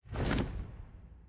minecraft / sounds / mob / phantom / flap4.ogg
flap4.ogg